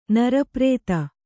♪ nara prēta